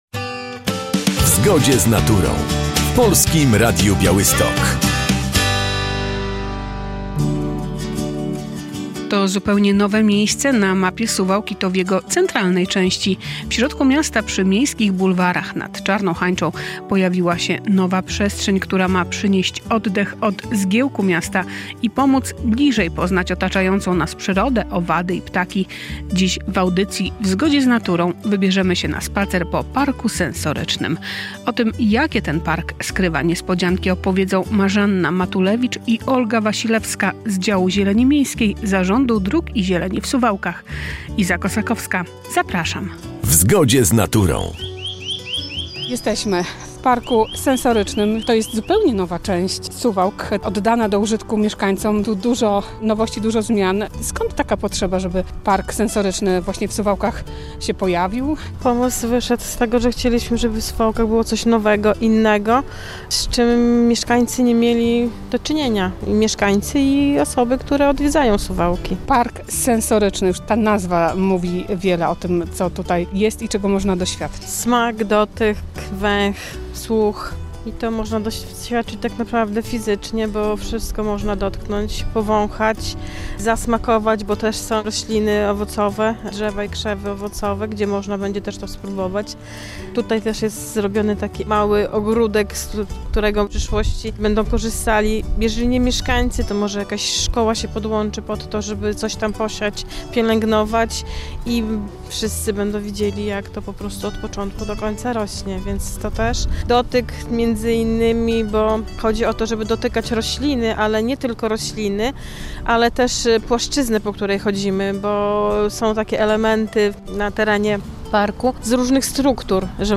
W audycji w Zgodzie z Naturą wybierzemy się na spacer po Parku Sensorycznym w Suwałkach.